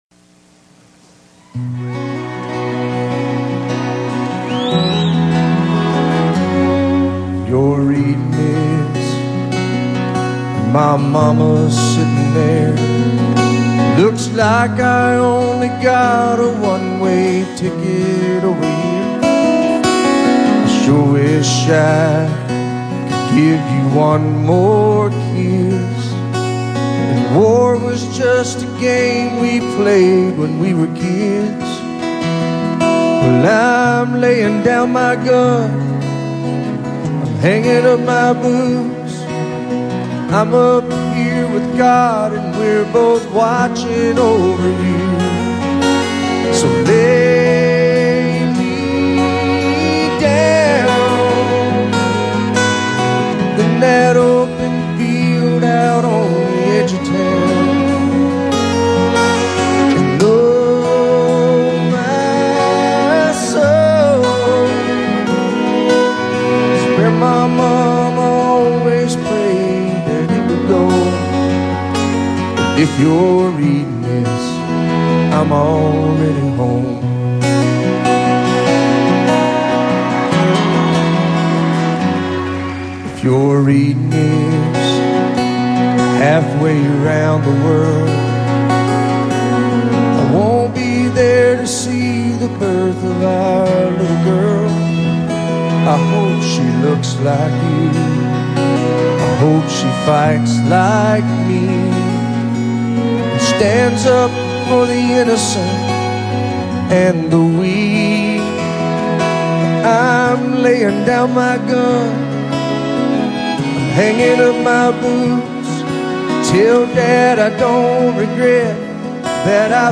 To date, there is no studio recording of it.